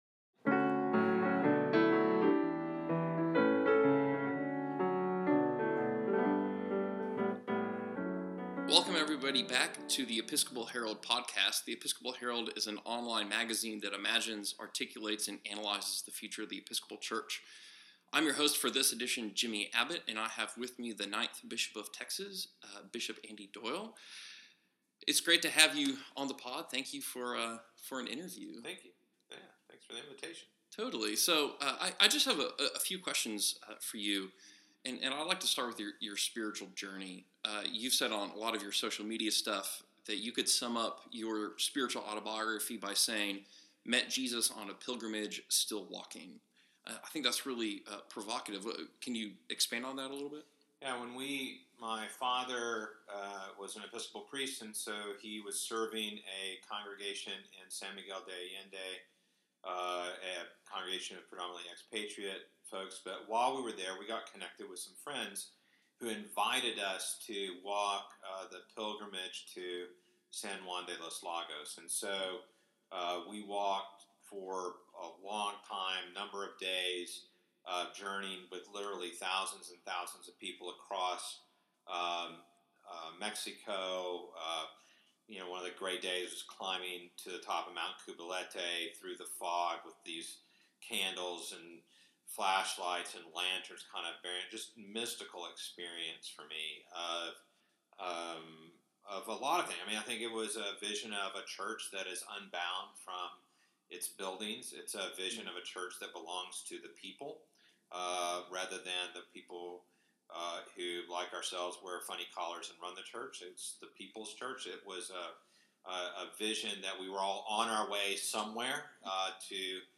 In our first edition of the Conversation Series on the Episcopal Herald Podcast we sit down with Bishop Doyle to talk about spirituality, transformation, and reconciliation.